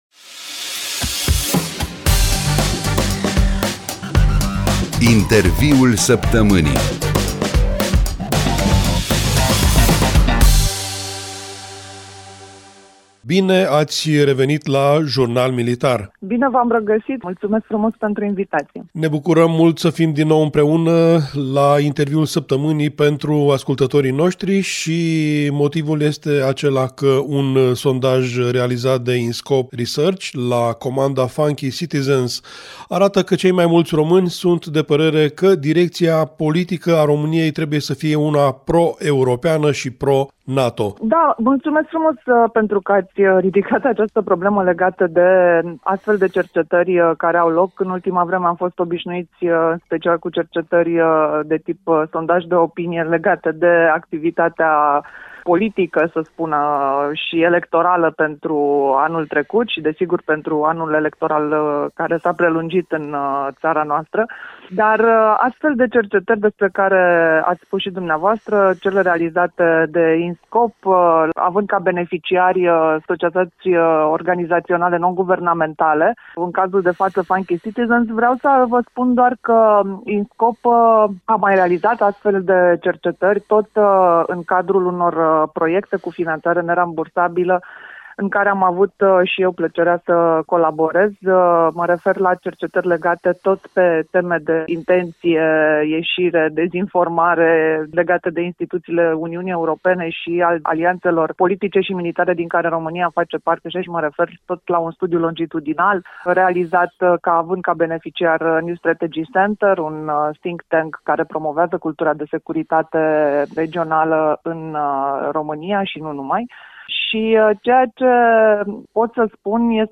Actualitatea militara » Interviul săptămânii